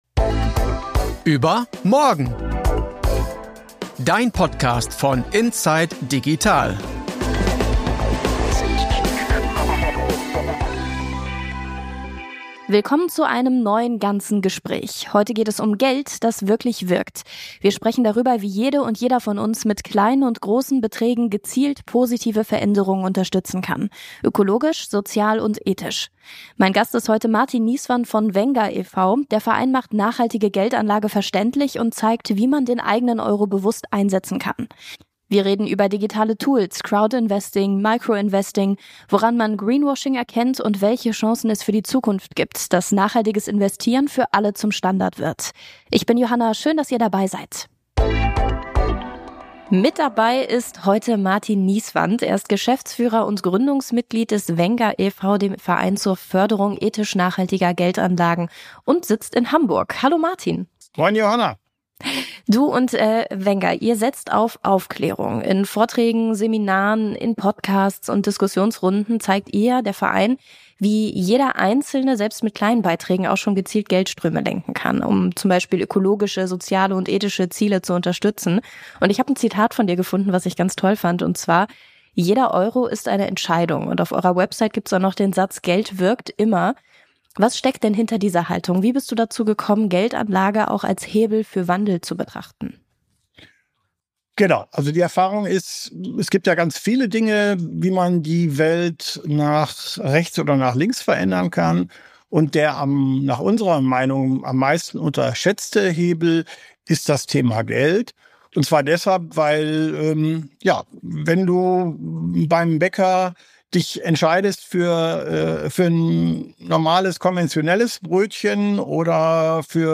Das Ganze Gespräch über nachhaltige Geldanlagen: Jeder Euro zählt ~ überMORGEN – dein Podcast von inside digital Podcast